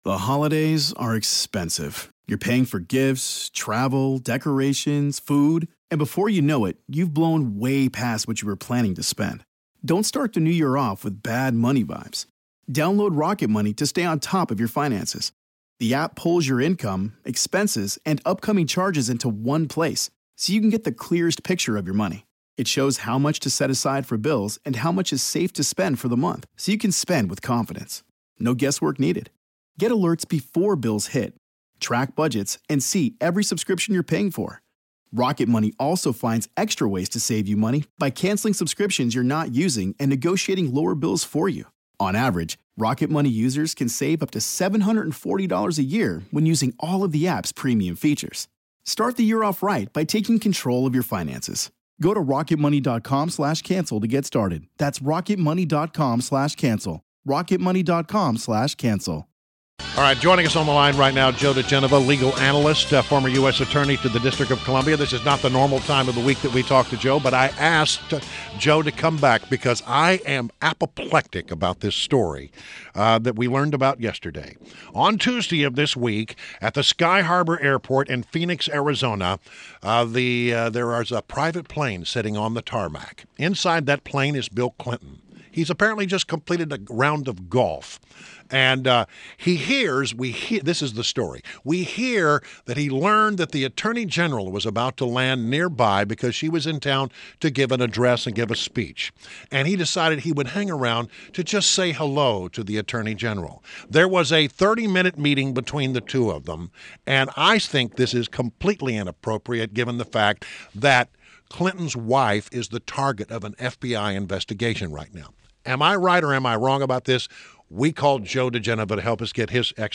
WMAL Interview - Joe Digenova - 06.30.16